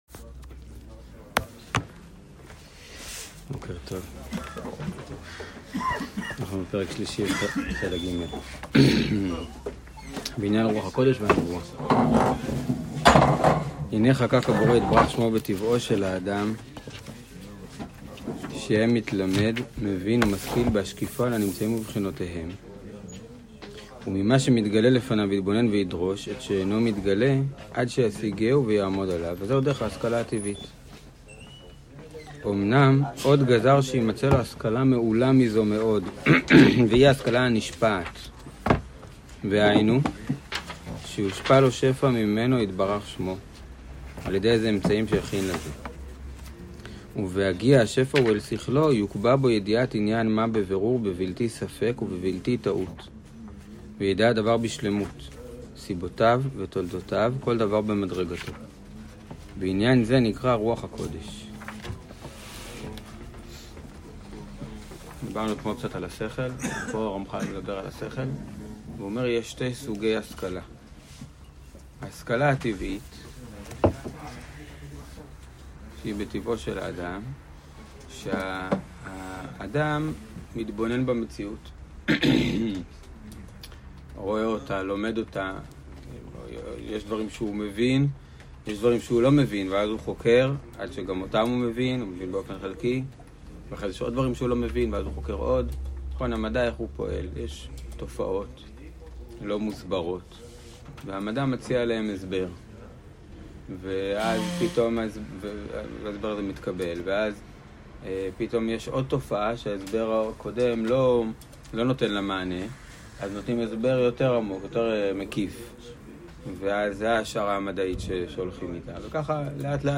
שיעור חלק ג'